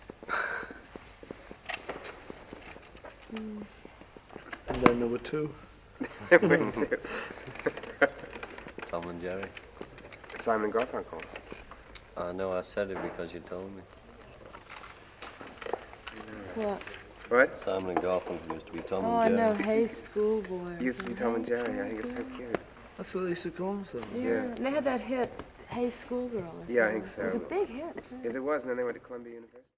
After an extended silence, and several audible sighs on the Nagra tapes, Paul uttered five of the most memorable words in all of the Get Back docuseries.
While it’s arguably the most poignant moment of the Beatles on film, it’s not exactly the same on tape.